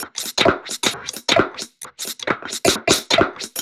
Index of /musicradar/uk-garage-samples/132bpm Lines n Loops/Beats
GA_BeatDSweepz132-07.wav